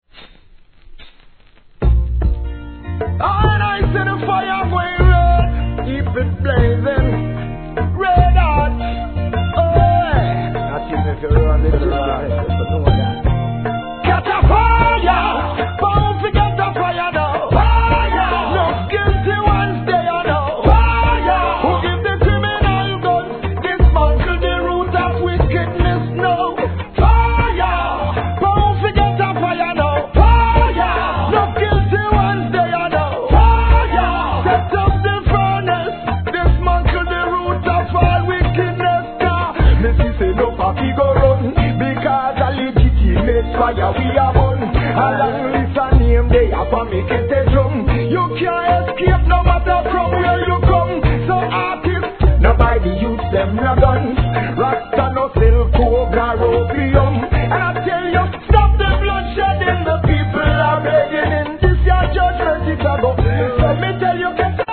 REGGAE
リメイクRHYTHM。